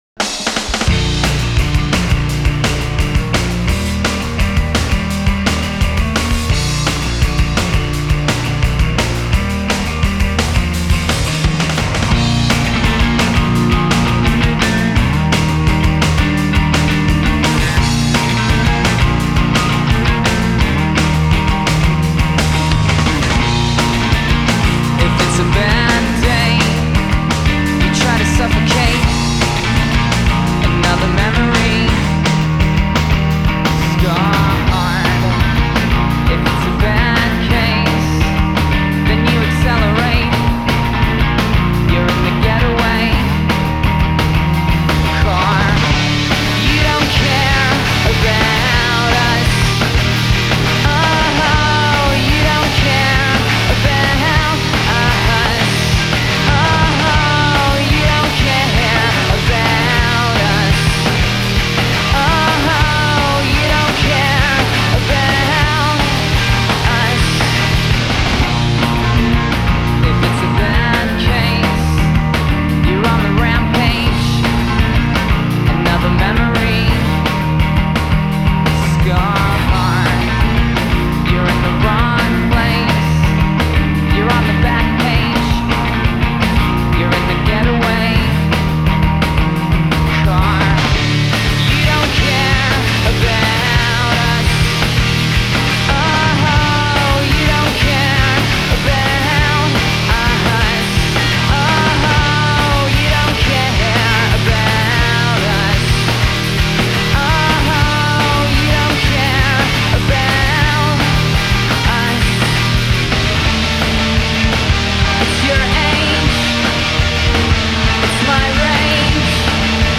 Альтернативный рок Постпанк Гранж